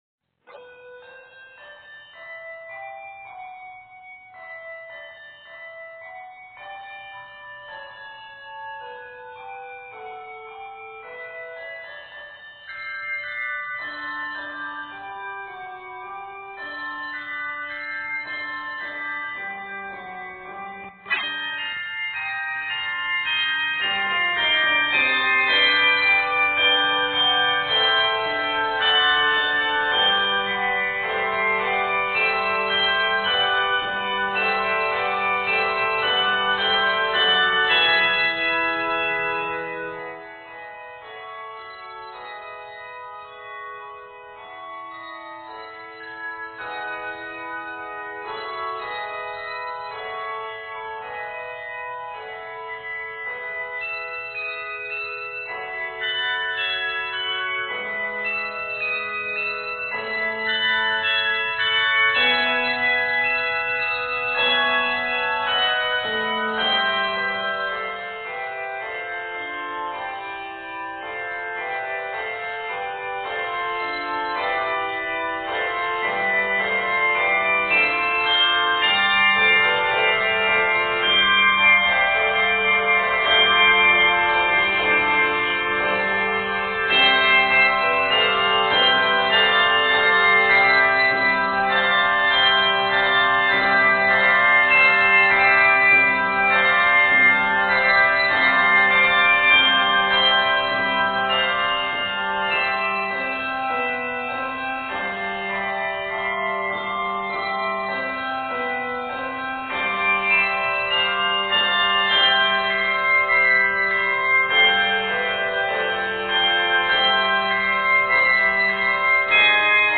handbells